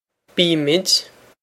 bímid bee-i-mid
Pronunciation for how to say
This is an approximate phonetic pronunciation of the phrase.